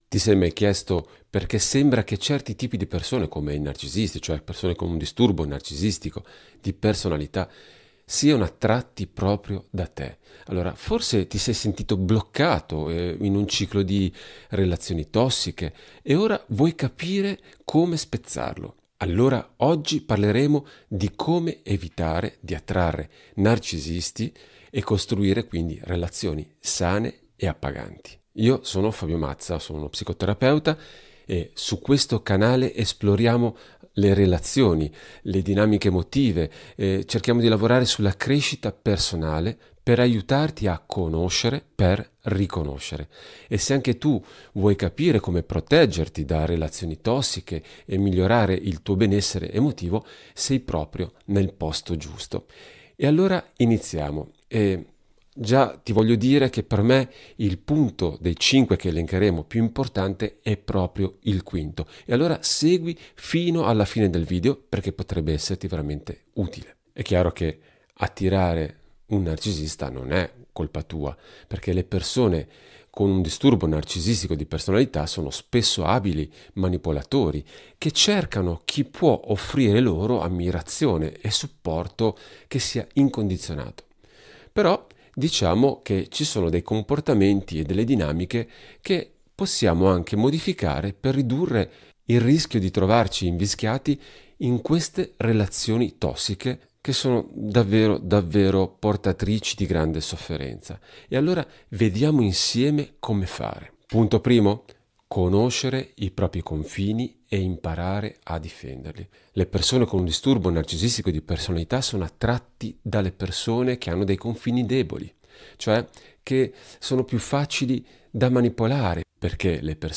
Live su narcisismo e relazioni, come riconoscerle e tutelarsi